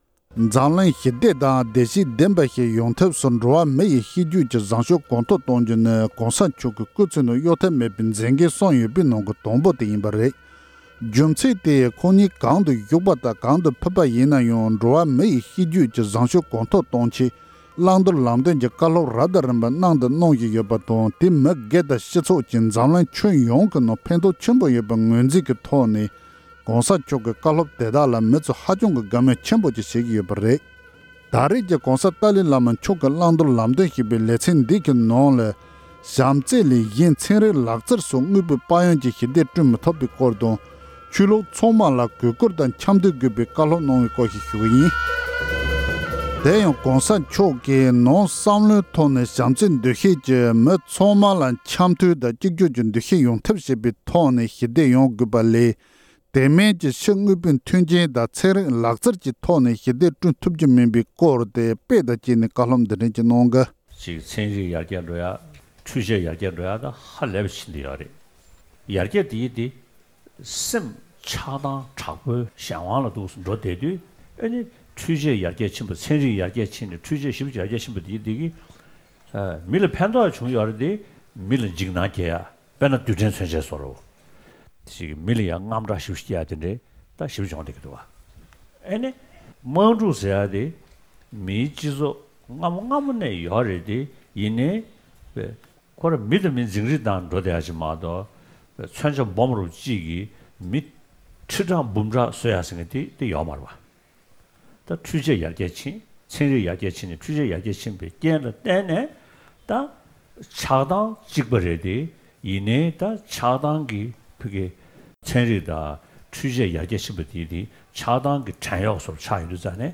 ༸གོང་ས་མཆོག་ནས་བྱམས་བརྩེ་ལས་གཞན་ཚན་རིག་སོགས་དངོས་པོའི་དཔལ་ཡོན་གྱིས་ཞི་བདེ་བསྐྲུན་མི་ཐུབ་པའི་སྐོར་བཀའ་སློབ།